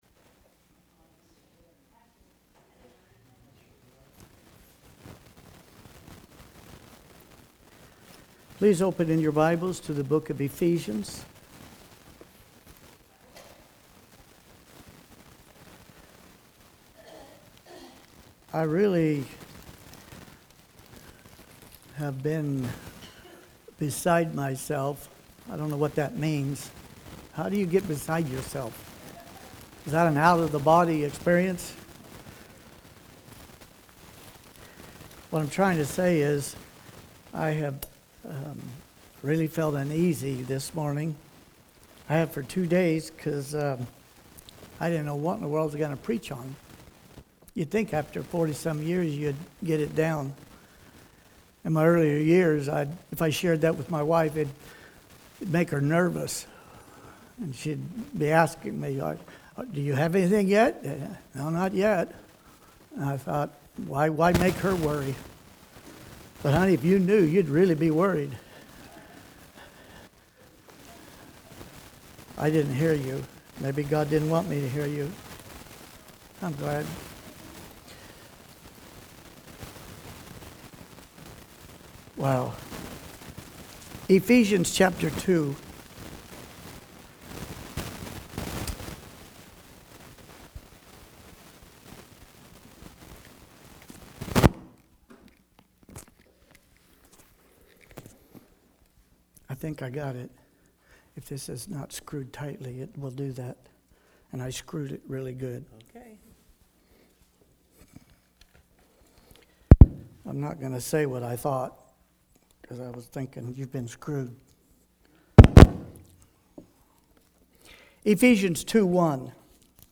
Sermon starts at 3:30
Morning Sermons